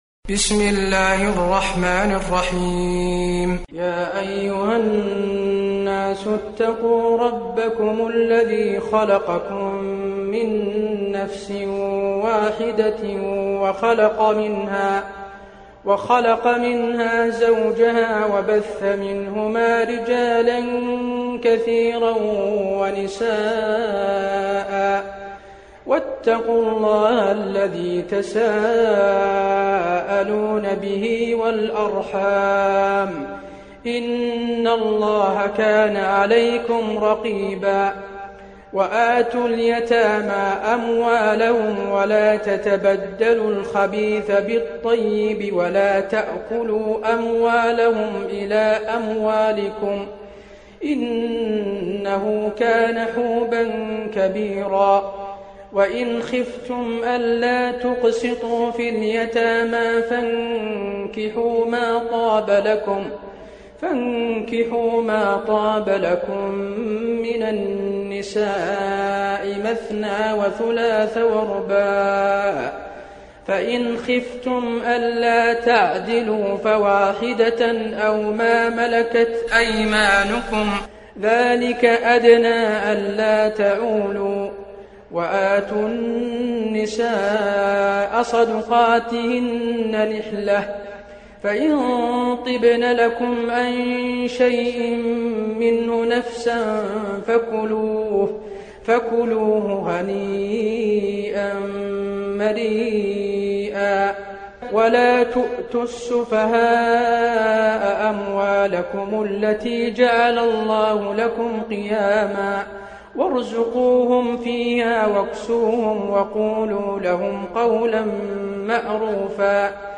المكان: المسجد النبوي النساء